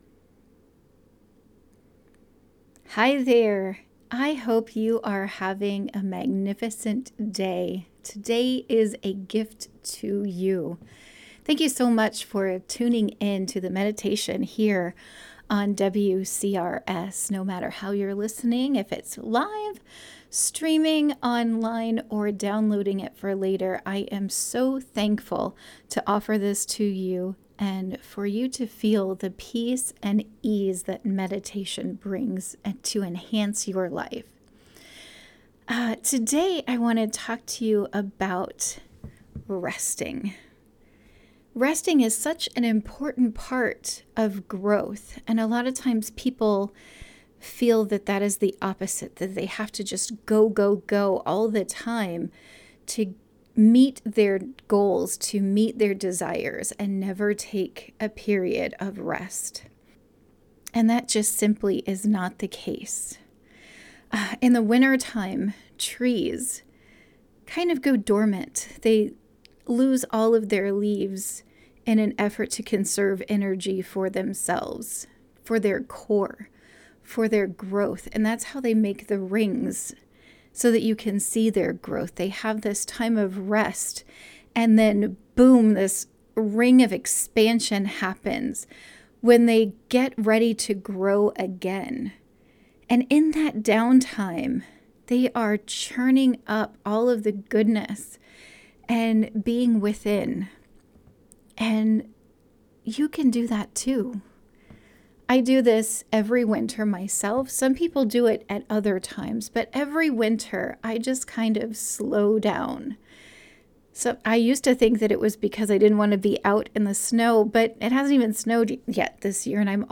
Morning Meditation